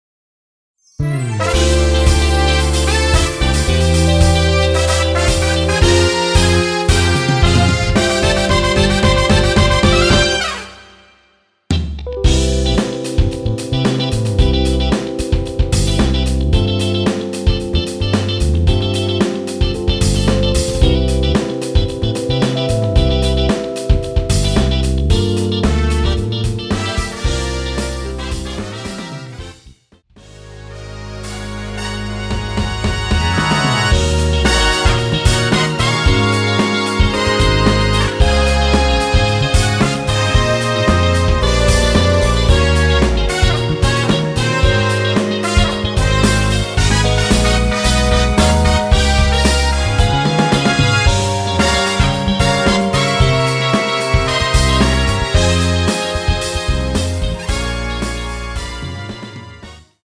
원키에서(+4)올린 MR입니다.
앞부분30초, 뒷부분30초씩 편집해서 올려 드리고 있습니다.